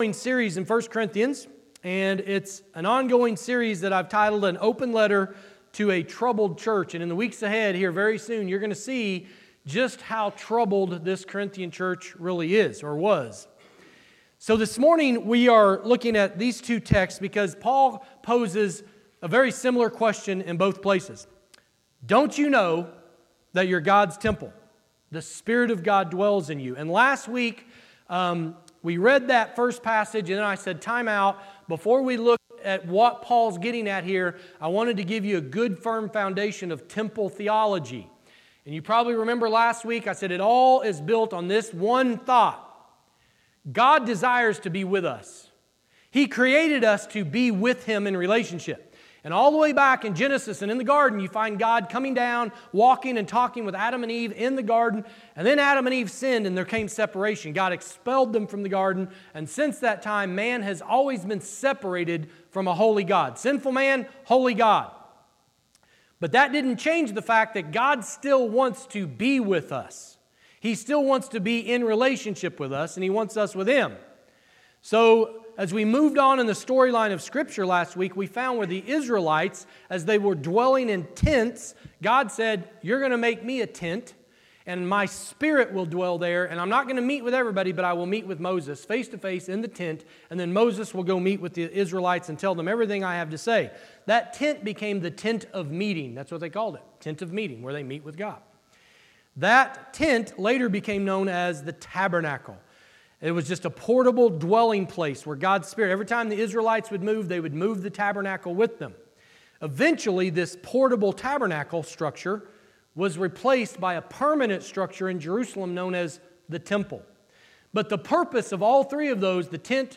Church in Action Sermon Podcast